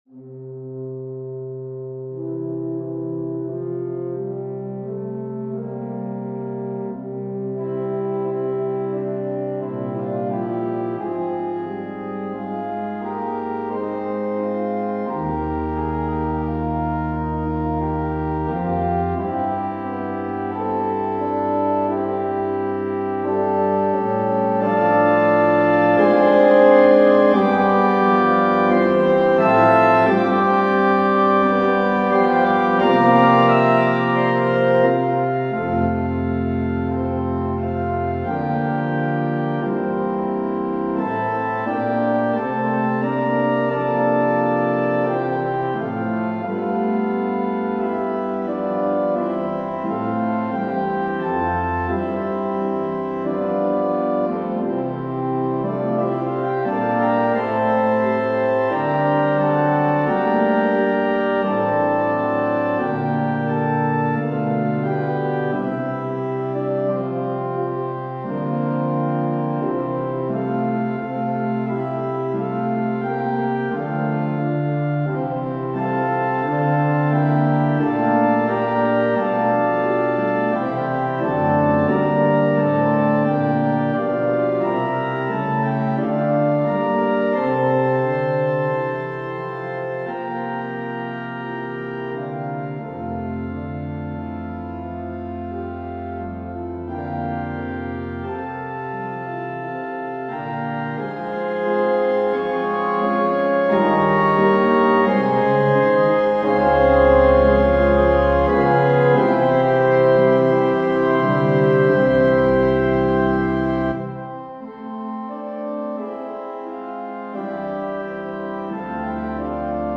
An organ solo arrangement with "The Chord" sequence (measures 62-65) by David Willcocks that has oft been imitated but never duplicated.
Voicing/Instrumentation: Organ/Organ Accompaniment We also have other 41 arrangements of " Oh, Come All Ye Faithful ".